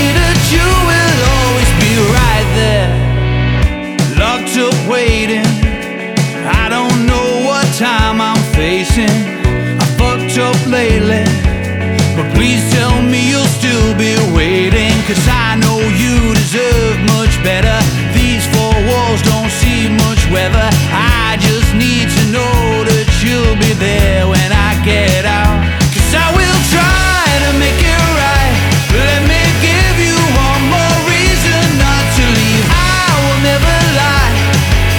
Жанр: Иностранный рок / Рок / Инди